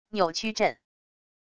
扭曲震wav音频